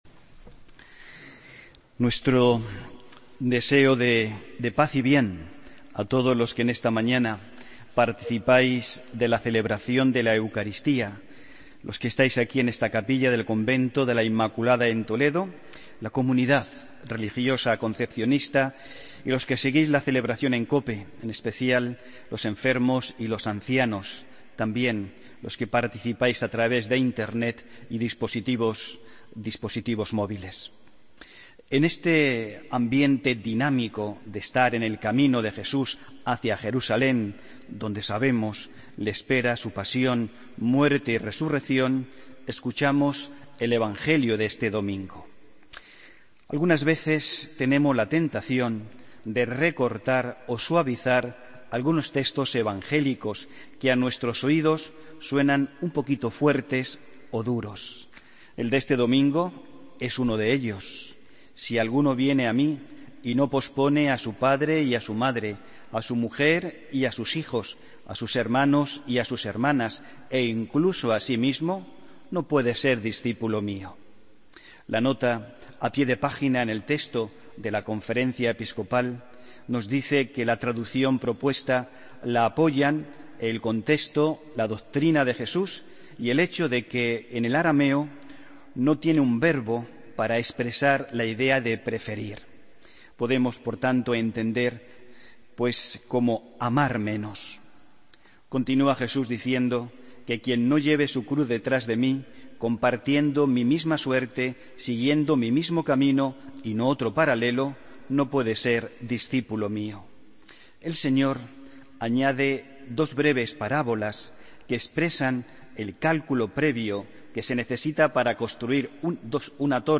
Homilía del domingo 4 de septiembre de 2016